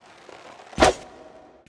rogue_attk_1.wav